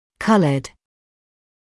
[‘kʌləd][‘калэд]цветной (US colored)